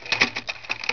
CameraClick.au